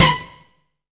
STRING HIT 2.wav